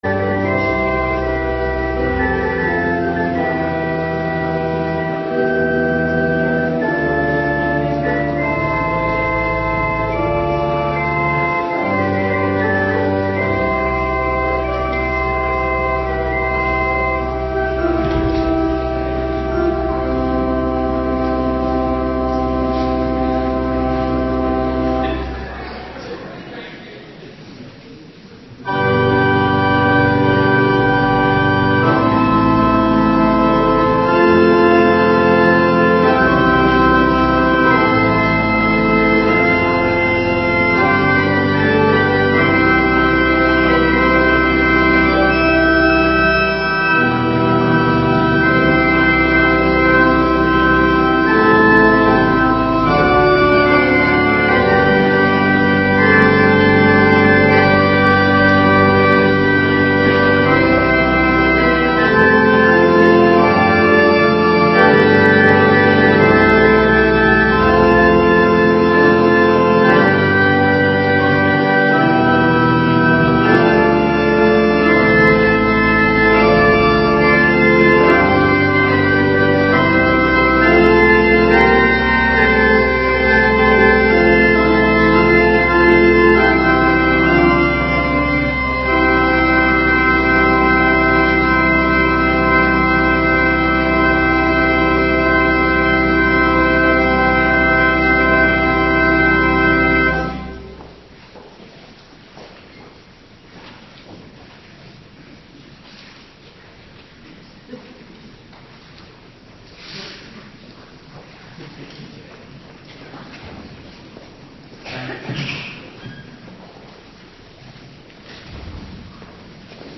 Avonddienst 11 januari 2026